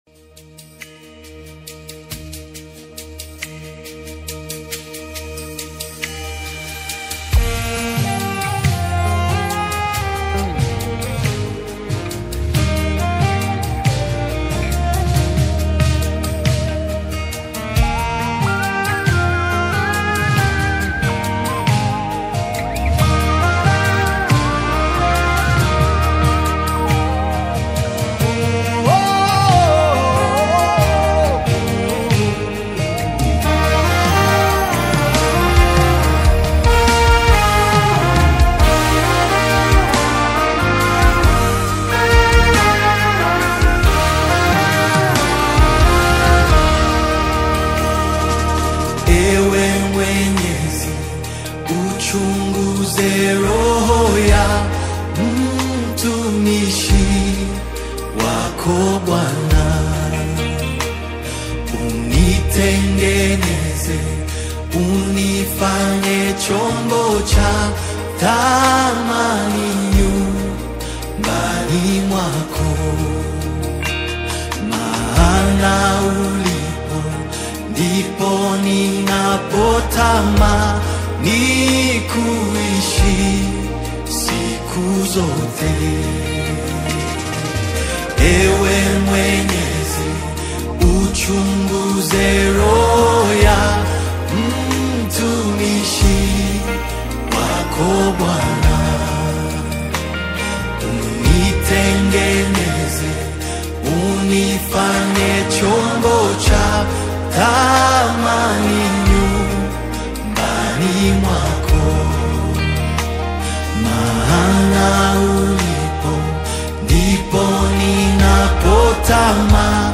prayer-song
gospel